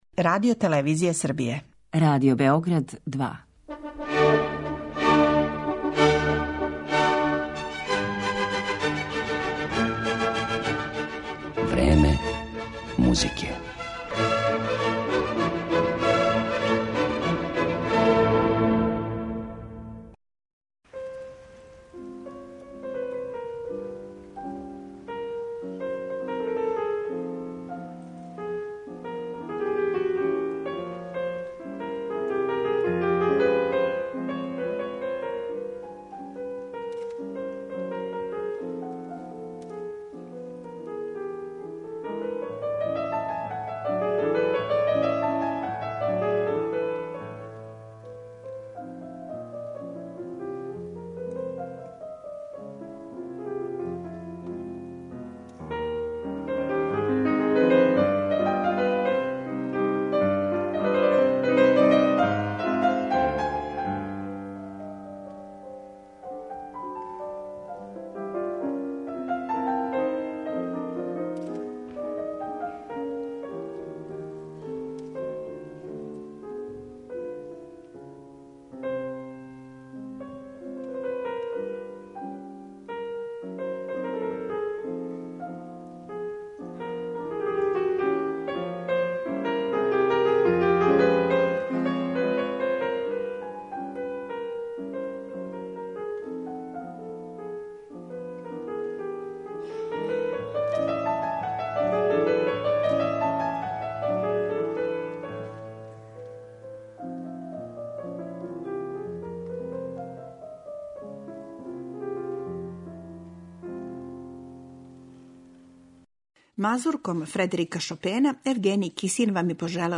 Данас ћете у емисији Време музике слушати разне игре словенских народа које су композитори романтизма употребили у својим остварењима, било као сегмент веће целине, односно, као тему симфоније или део балетског дивертисмана у опери, било као један од ставова свите, или, пак, као самосталну композицију.